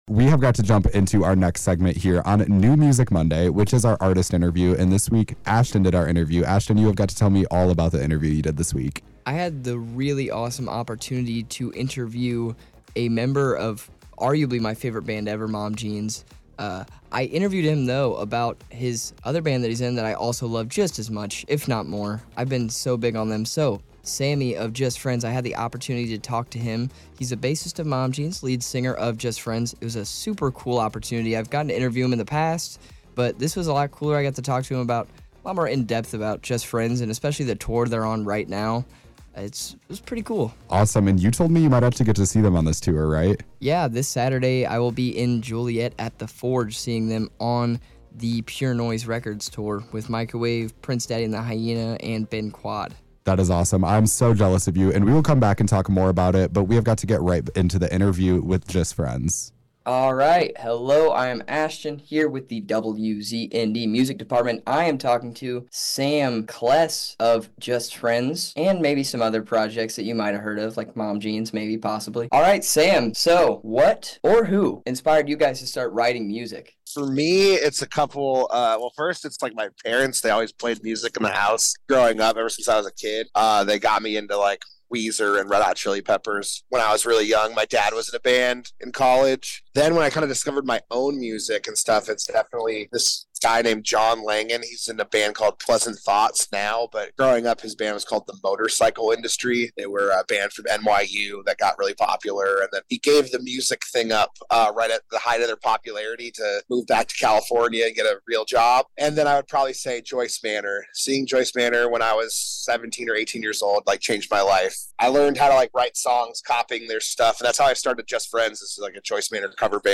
Just-Friends-Interview.mp3